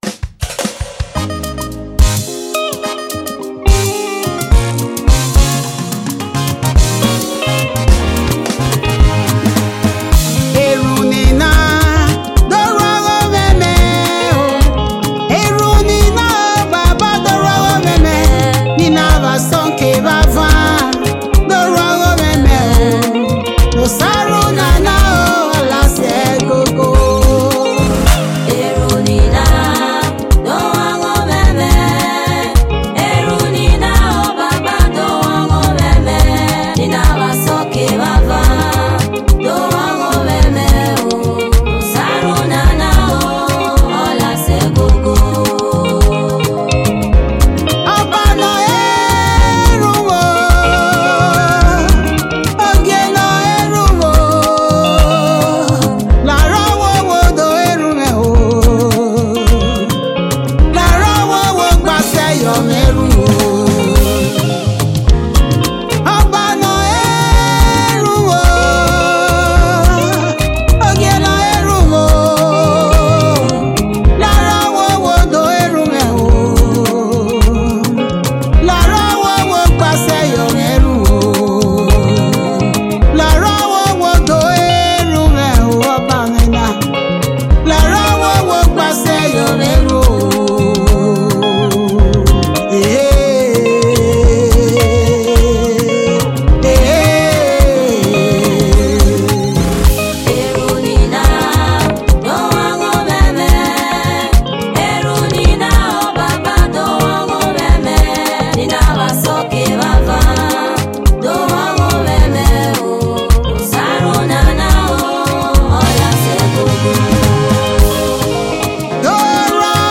Gospel music
passionate vocals